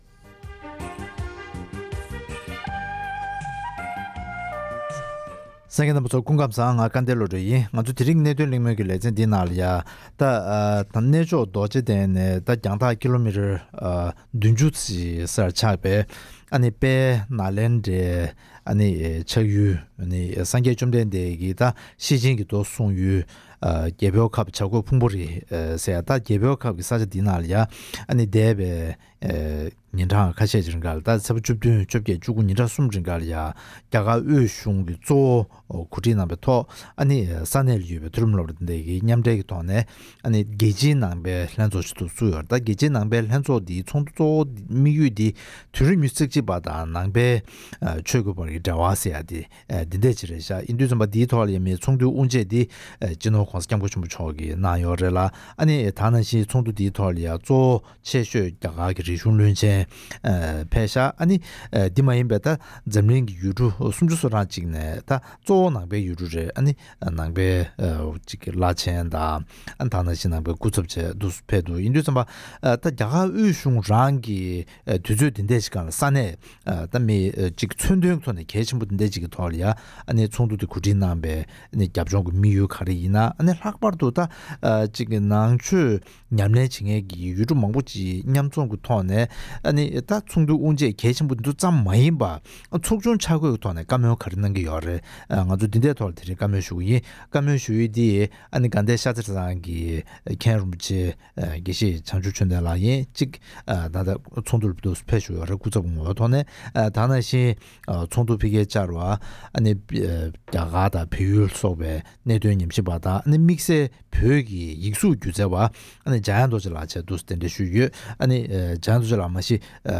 རྒྱ་གར་གཞུང་རང་གིས་རྒྱལ་སྤྱིའི་ནང་པའི་ལྷན་ཚོགས་གོ་སྒྲིག་གནང་དགོས་པའི་རྒྱབ་ལྗོངས་དང་དམིགས་ཡུལ་སོགས་ཀྱི་ཐད་གླེང་མོལ་གནང་བ།